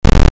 dig.ogg